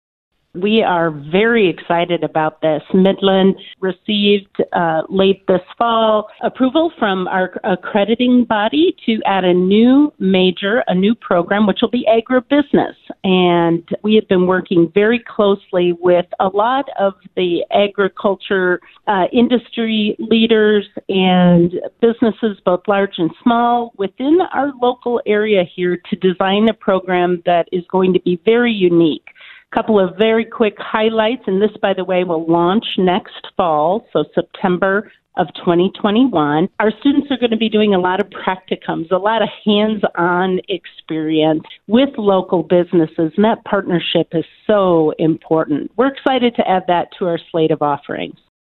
was a guest on the KHUB Morning Show on Thursday